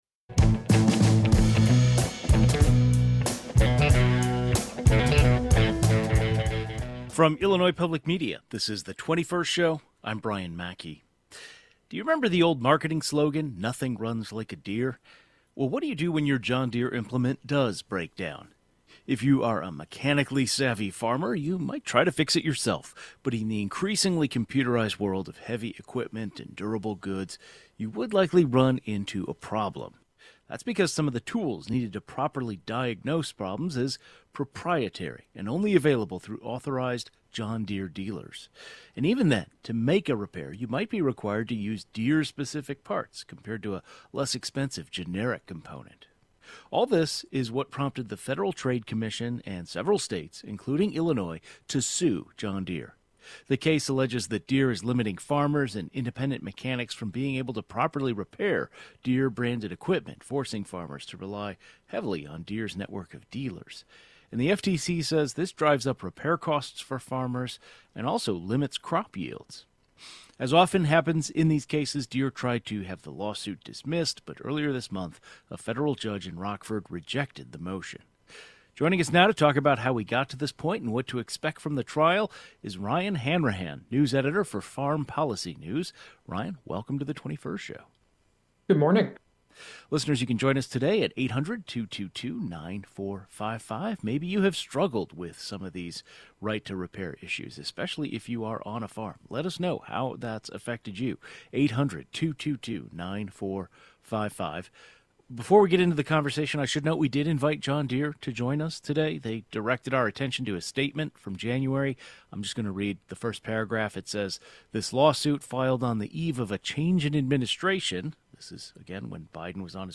A journalist covering agriculture weighs in on what has transpired so far in this case and what to expect from the trial.